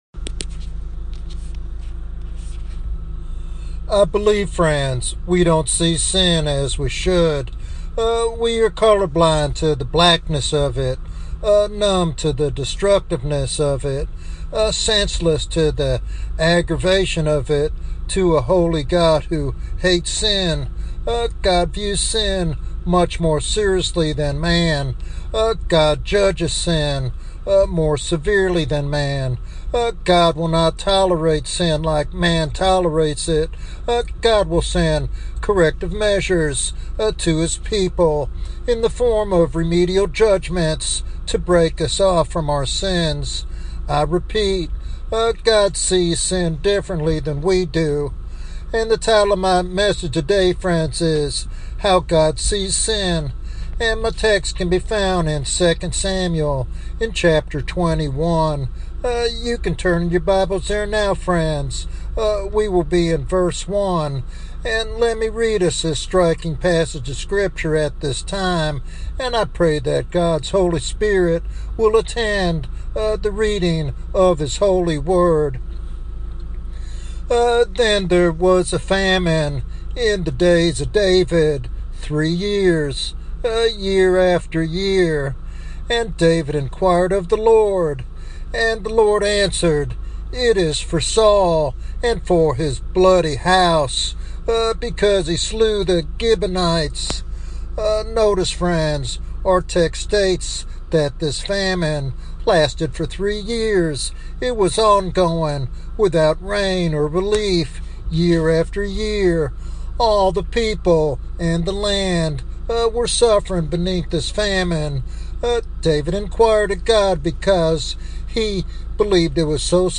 The sermon calls believers to a sober understanding of sin's gravity and urges heartfelt repentance and transformation. Listeners are challenged to see sin through God's eyes and respond with obedience and reverence.